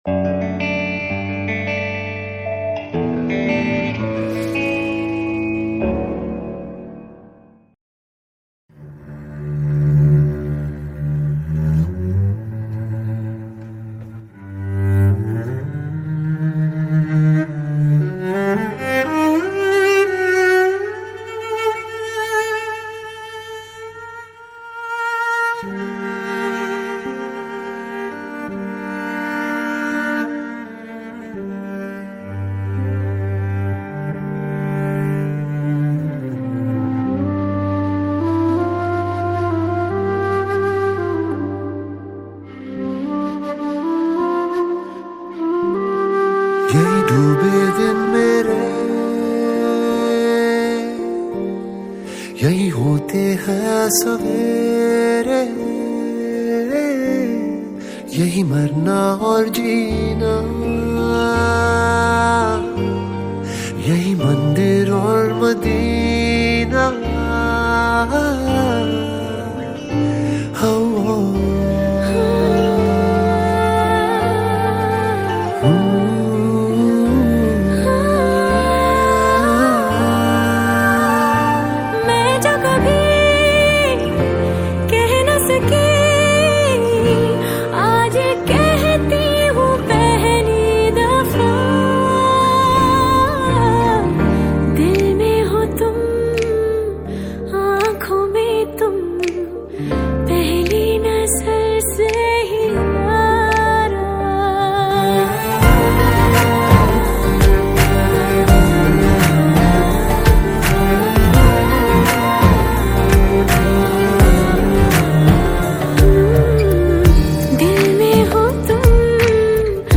Hindi Mixtape Songs